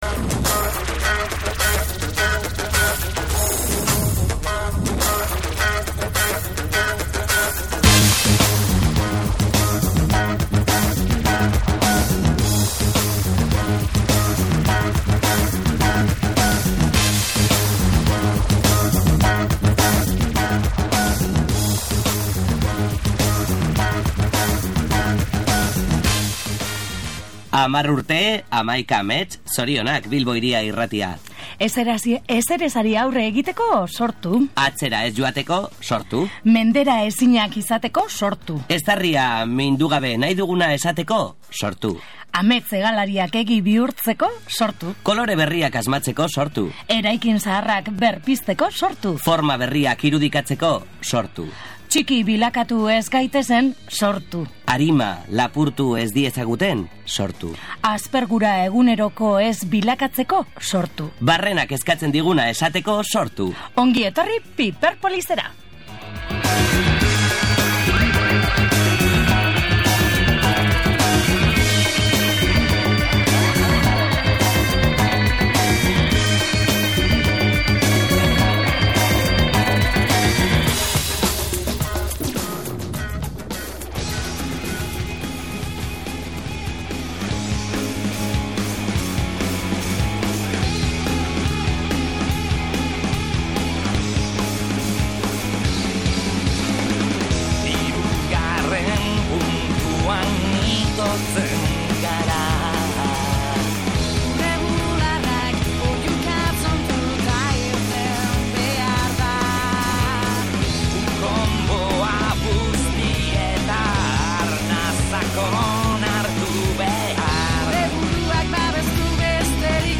Nazioarteko liburu eguna dela eta gure apaleko liburu ezberdinen pasarteak irakurri ditugu, irakurketaz gozatuz.